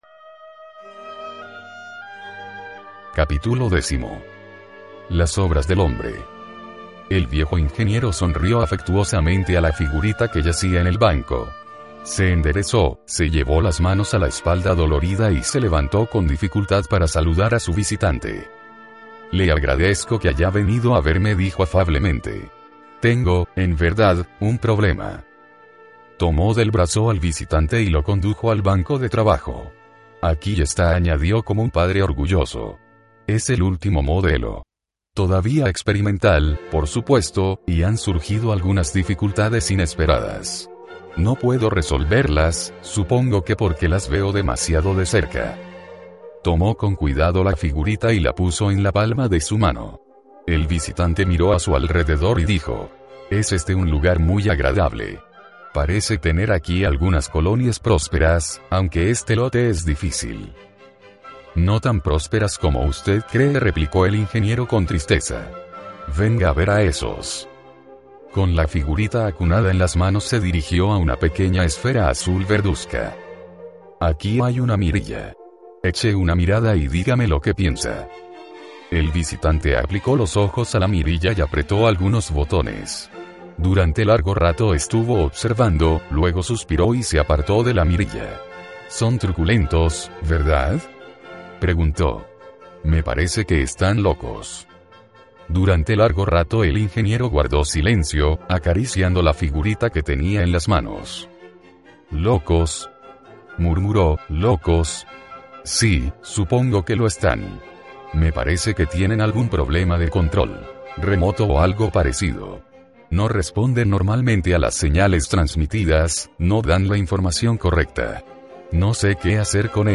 Audiolibros de Lobsang Rampa